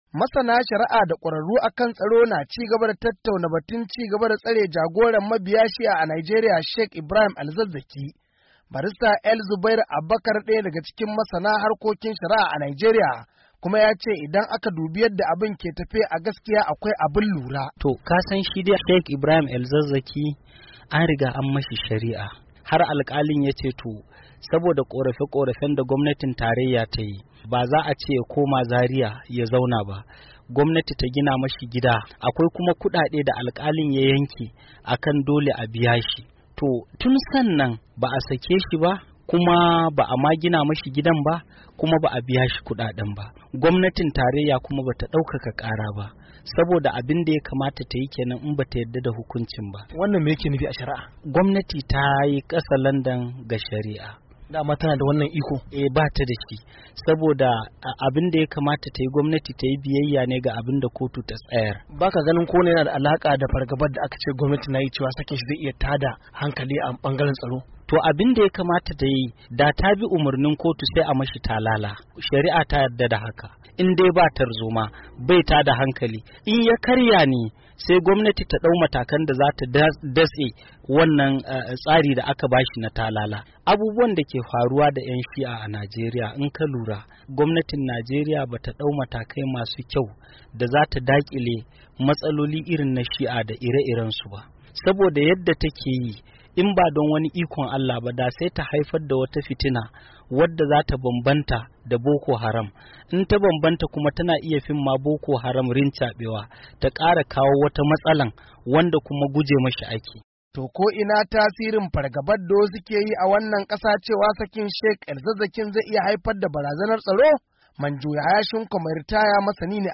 Wakilinmu a Kaduna